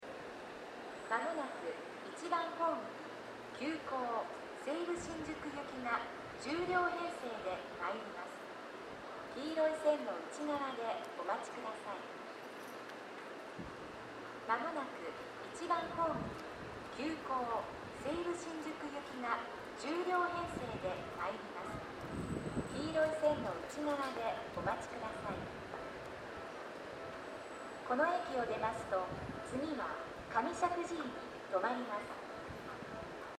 音量は大きめです。スピーカーも設置されており音質もそこそこです。
接近放送急行　西武新宿行き接近放送です。